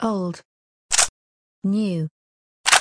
slightly_better_galil_clipin_44cc4.mp3